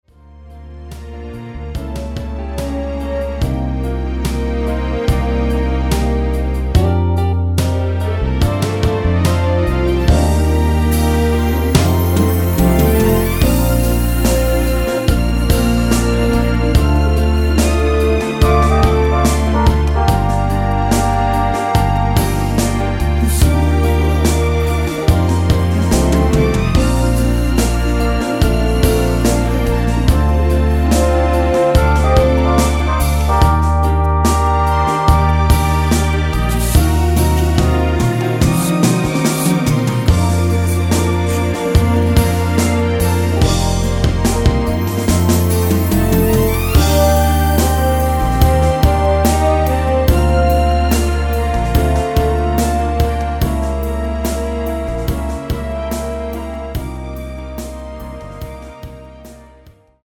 원키 코러스 포함된 MR 입니다.(미리듣기 참조)
Bb
앞부분30초, 뒷부분30초씩 편집해서 올려 드리고 있습니다.